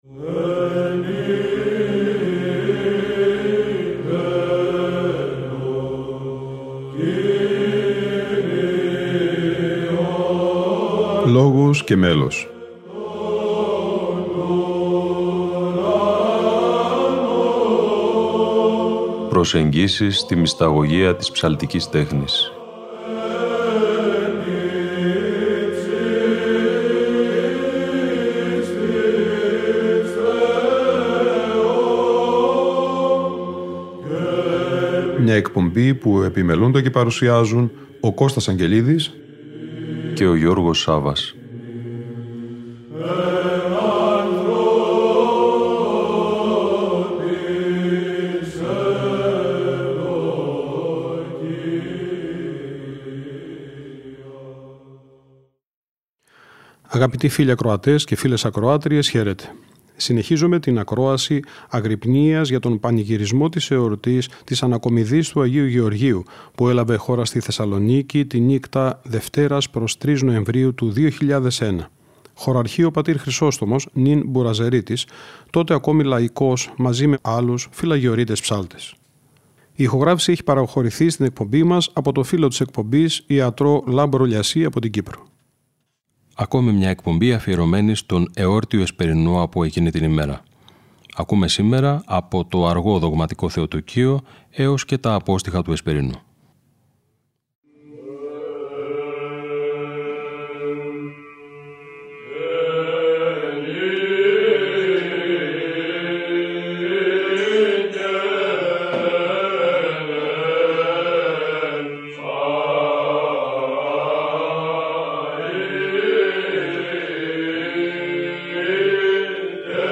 Αγρυπνία Ανακομιδής Λειψάνων Αγίου Γεωργίου - Ροτόντα 2001 (Γ΄)